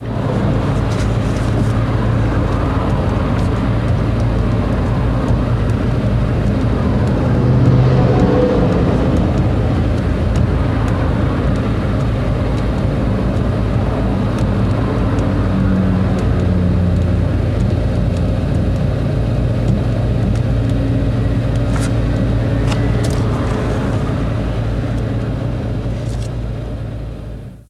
Motor en continuidad desde el interior de un coche Golf
motor
Sonidos: Transportes